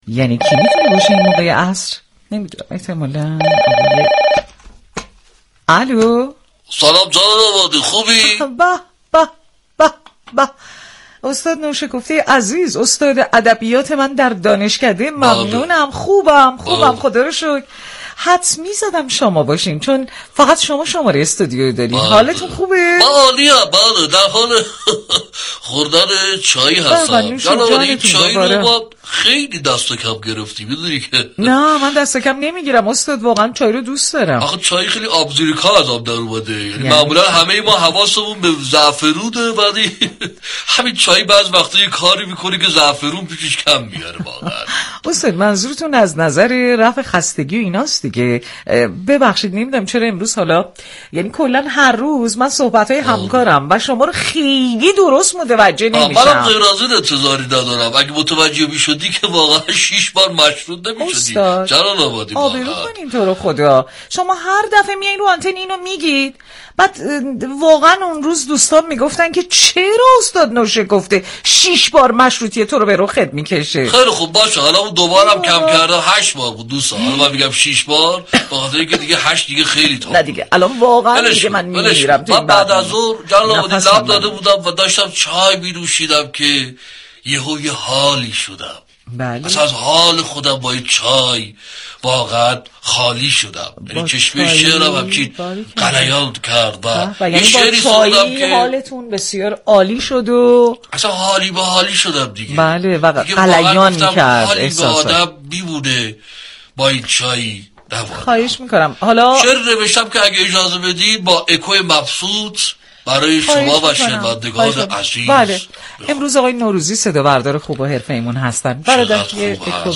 رادیو صبا دربرنامه زنده «عصر صبا» با طنازی به نقد فساد اقتصادی اخیر در بازار چایی پرداخت.
به گزارش روابط عمومی رادیو صبا، «عصر صبا» عنوان مجله رادیویی عصرگاهی این شبكه رادیویی است كه با شعار «یك عصر و این همه خبر» در فضایی شاد و متنوع مخاطبان را در جریان اطلاعات و اخبار روز جامعه قرار می‌دهد.